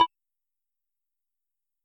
SFX_UI_MenuSelections.mp3